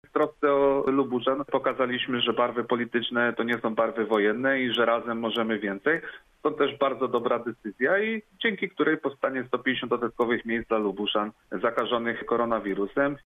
Na naszej antenie działania skomentowali politycy.